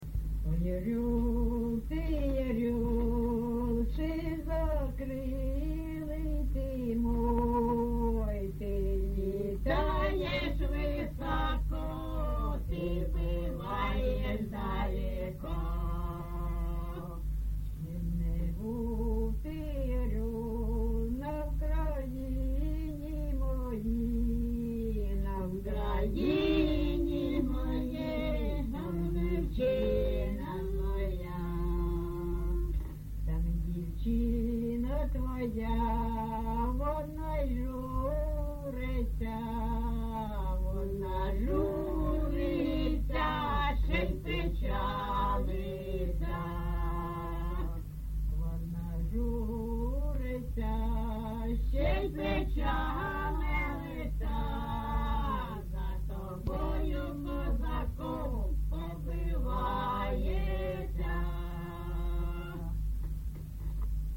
ЖанрПісні з особистого та родинного життя
Місце записус. Званівка, Бахмутський район, Донецька обл., Україна, Слобожанщина
(+невідомі жінки)